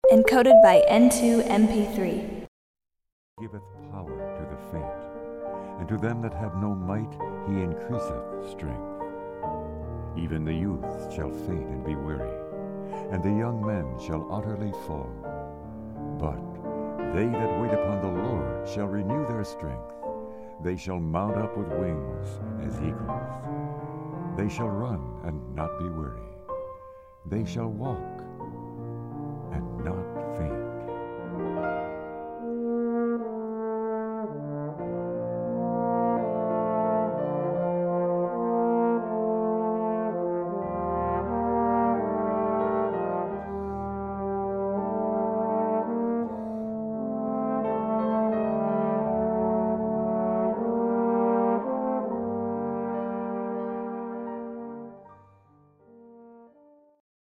Recorded at: Sonic Temple, Roslindale (Boston), MA
Bass Trombone
piano.